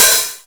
Index of /90_sSampleCDs/Club_Techno/Percussion/Hi Hat
Hat_Fat_1.wav